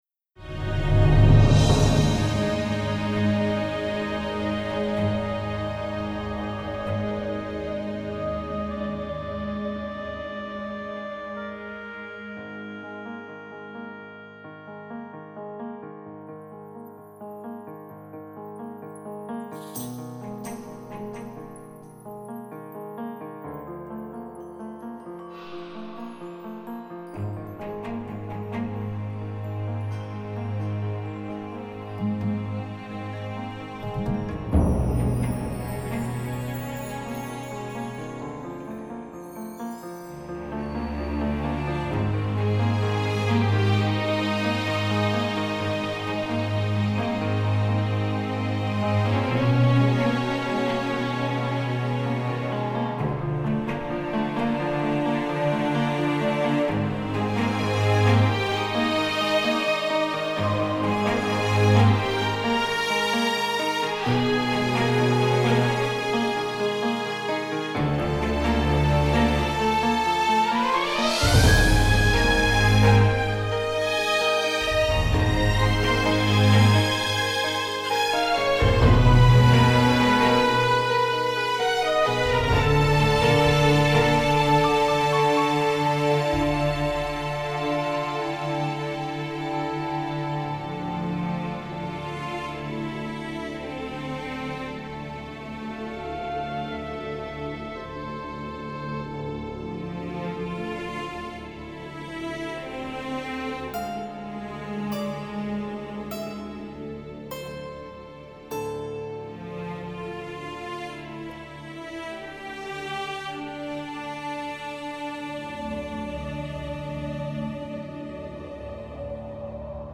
Musicgenre: DEUTSCHE SONGS, TOP 20